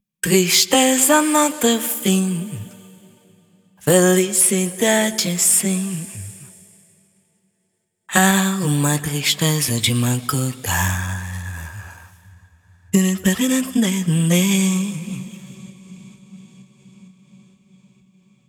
Portugese Women Vocal
portugese-feeling-vocal-sad_G_minor.wav